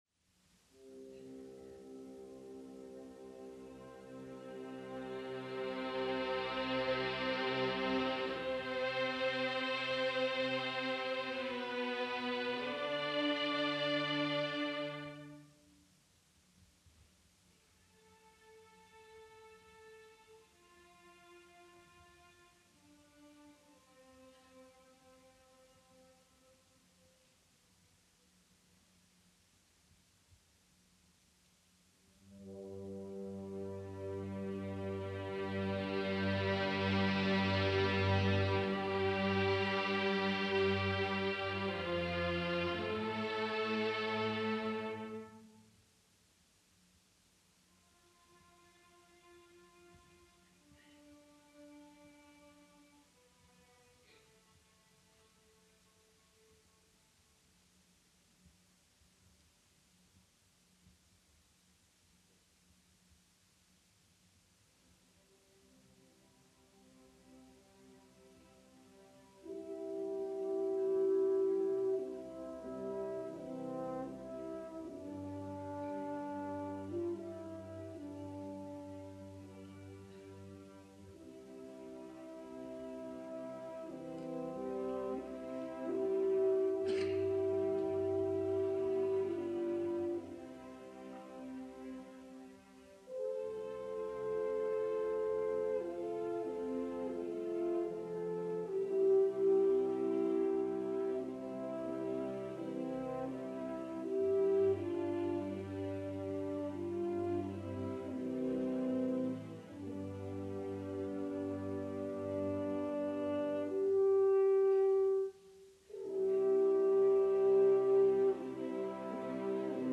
Eduard van Beinum
This recording was made in 1955 for radio broadcast by The Cleveland Orchestra led by Eduard van Beinum.
The Cleveland Orchestra